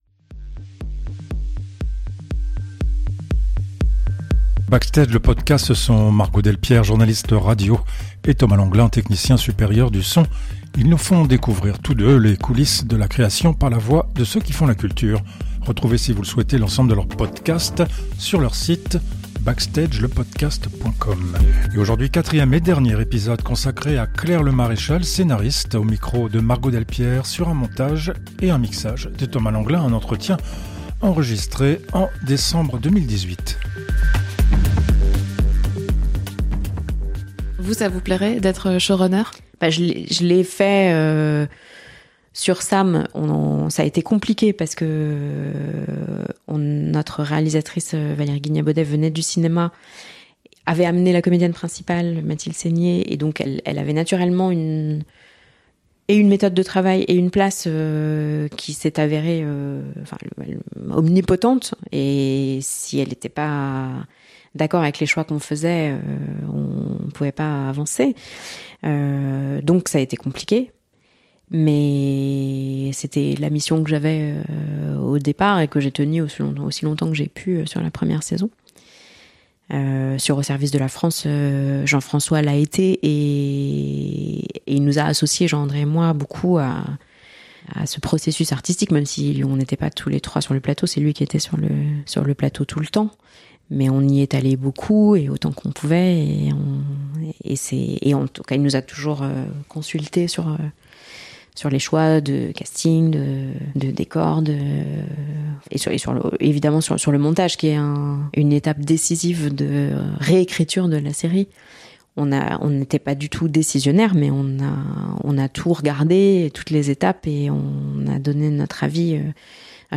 Entretien enregistré en décembre 2018.